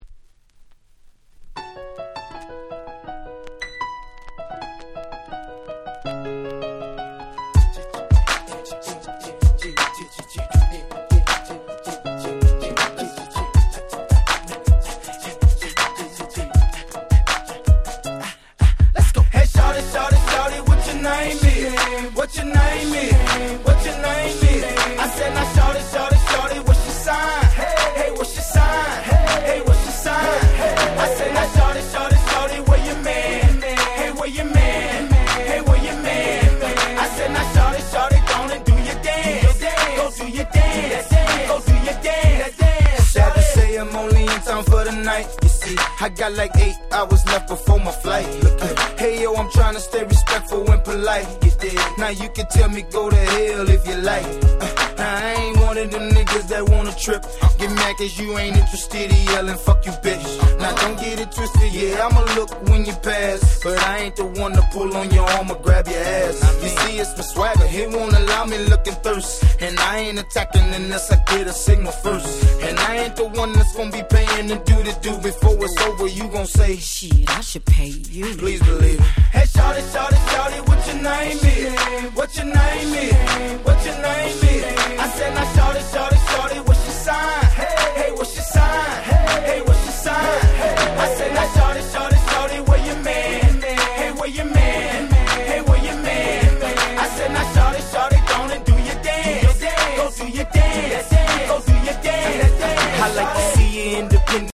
07' Mellow Hip Hop Classic !!
キャッチー系 キラキラ系 00's